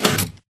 MinecraftConsoles / Minecraft.Client / Windows64Media / Sound / Minecraft / tile / piston / out.ogg